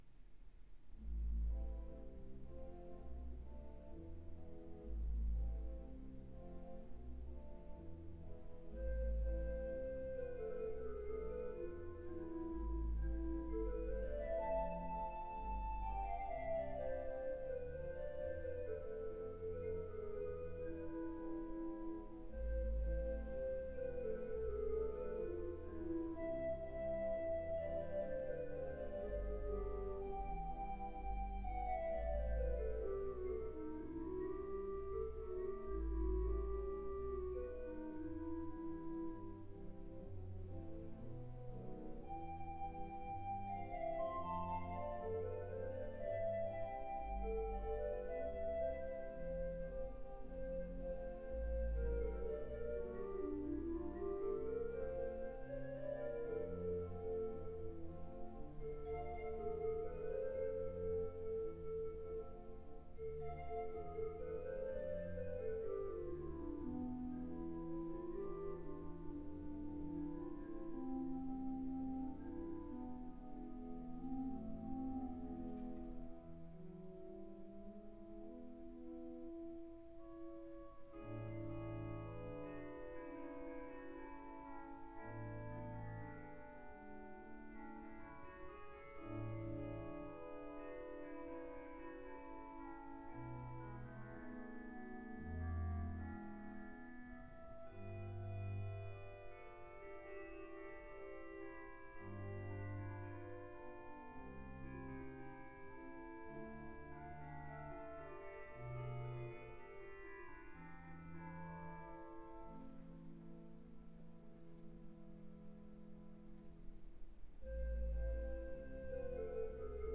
Hope-Jones organ. "Cantilene".
Illumination Night.